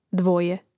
dvȍje dvoje